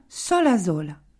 sola sola [ s ] : sourde